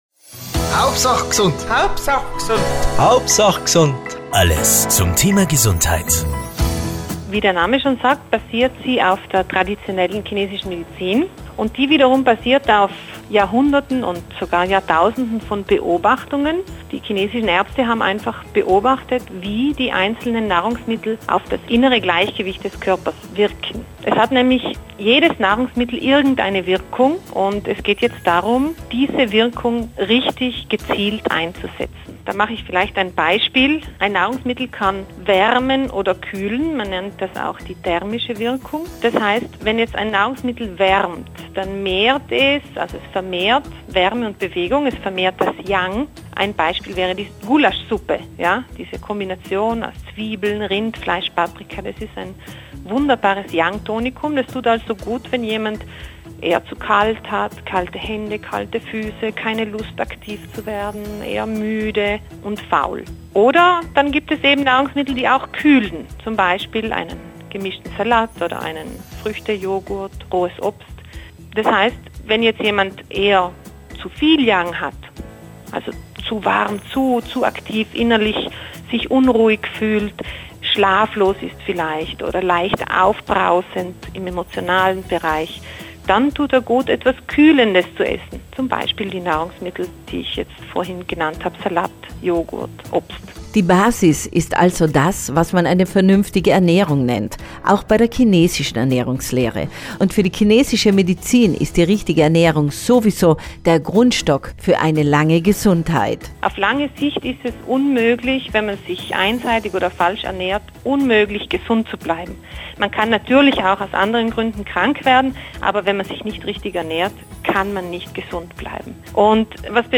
Beitrag-H.G.-02-07-mit-Jingle.mp3